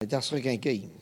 Collectif patois et dariolage
Catégorie Locution